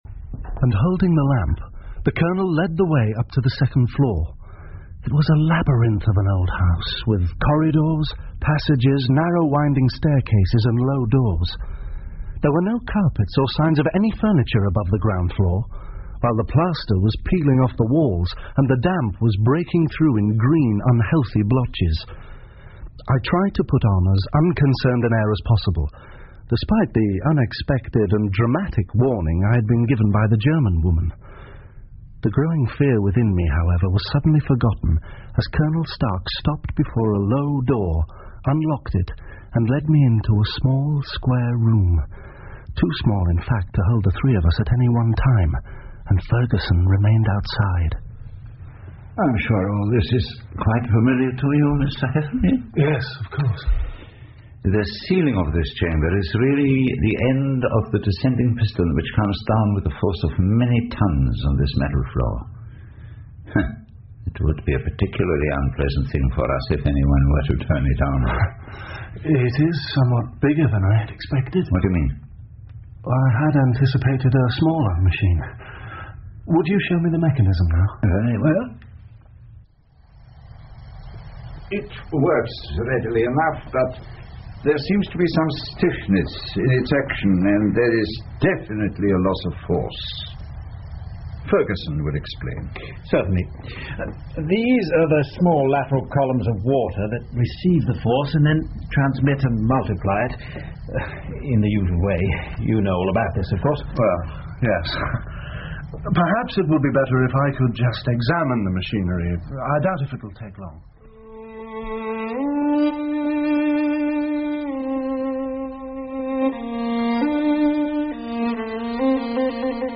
福尔摩斯广播剧 The Engineer's Thumb 5 听力文件下载—在线英语听力室